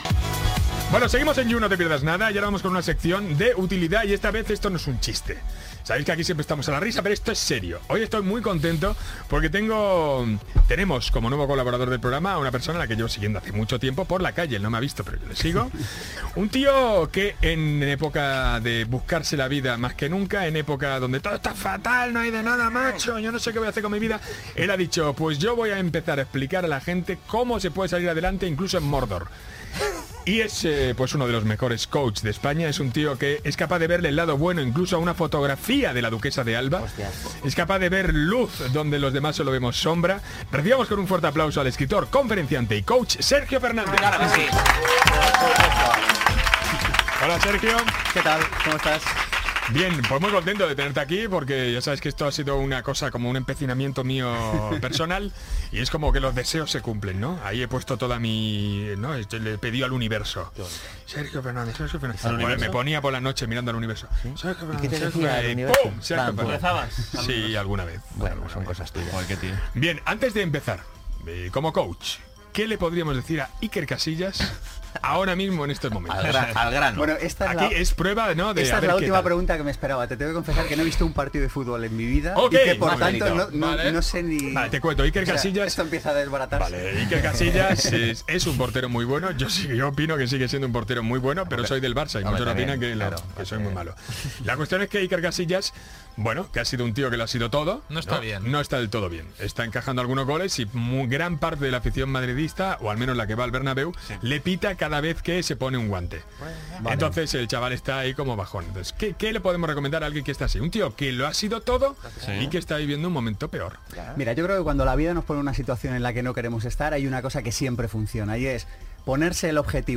Identificiació del programa i entrevista